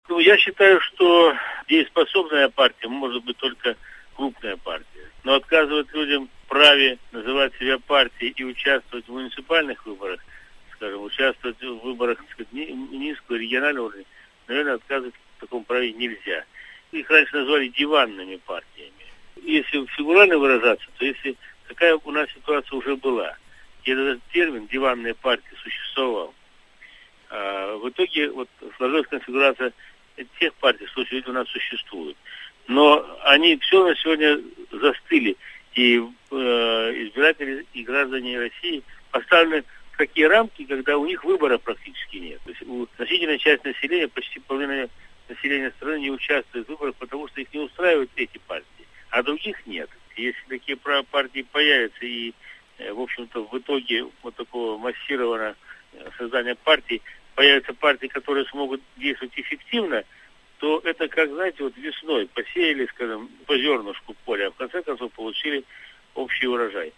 Такое мнение высказал депутат Госдумы Вячеслав Позгалев, комментируя корреспонденту ИА «СеверИнформ — Новости Вологодской области» принятие закона о политических партиях.
Вячеслав Позгалев рассказывает о принятии закона о политических партиях